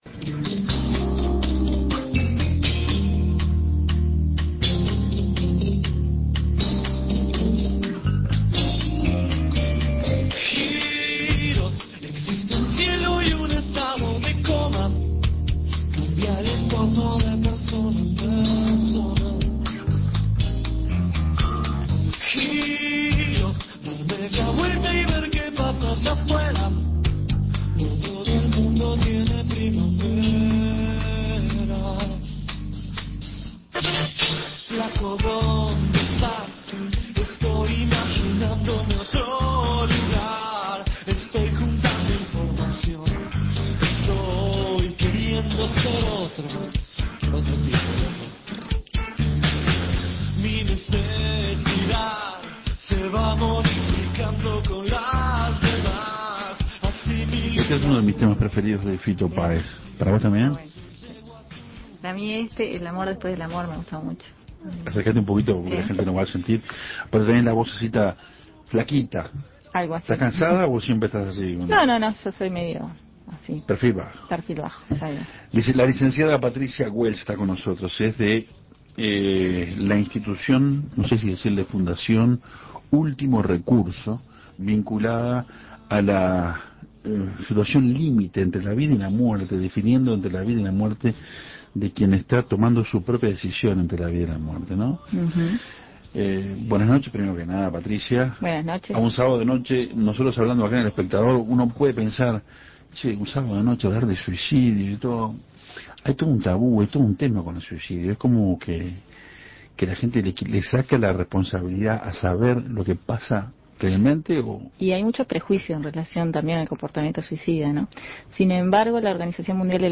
Todos enfrentan situaciones límites en la vida, pero hay algunos que necesitan ayuda para enfrentarlas. Para eso existe la fundación "Último Recurso", que trabaja en la prevención del suicidio. En entrevista con Café Torrado